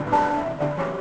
output / piano / 34-0.wav